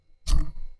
metalSlideOnMetal3.wav